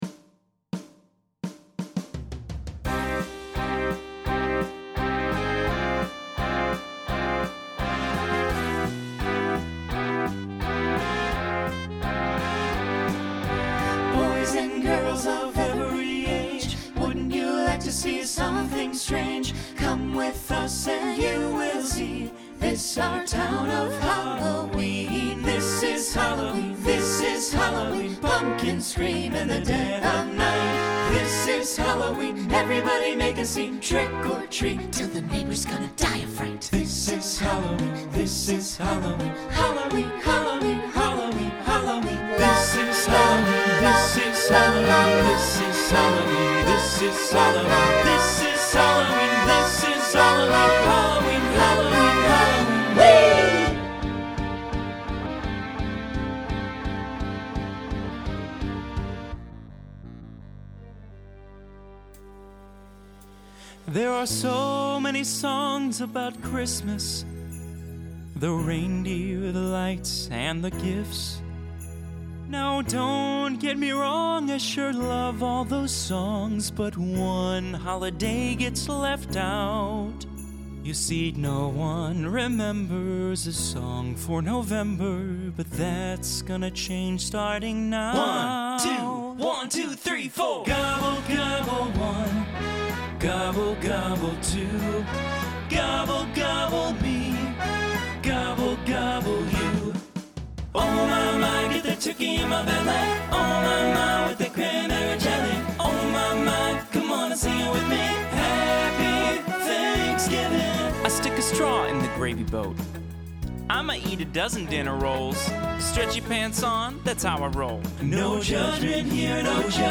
SATB quartet
SSA
Genre Broadway/Film , Holiday , Pop/Dance
Voicing Mixed